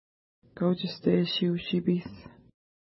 Pronunciation: ka:wtʃəsteʃi:u-ʃi:pi:s
Pronunciation